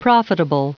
Prononciation du mot profitable en anglais (fichier audio)
Prononciation du mot : profitable